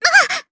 Rehartna-Vox_Damage_jp_01.wav